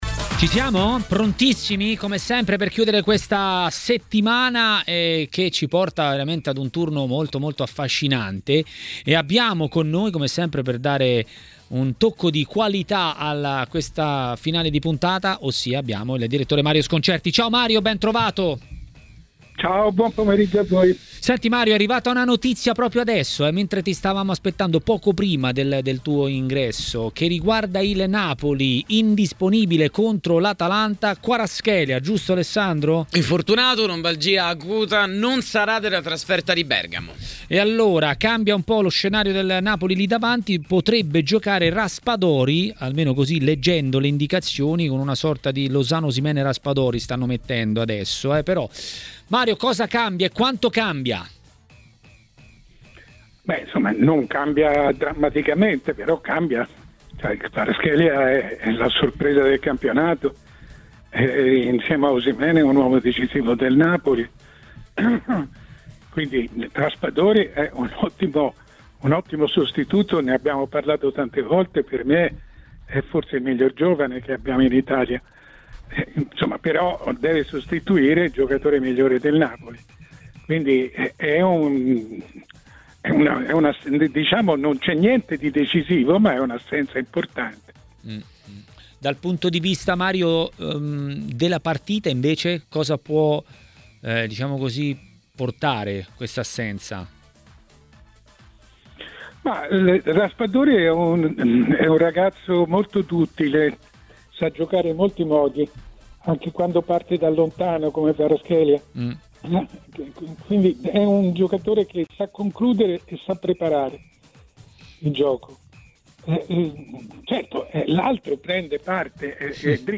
A Tmw Radio, durante 'Maracanà', per commentare il weekend di Serie A è intervenuto il direttore Mario Sconcerti.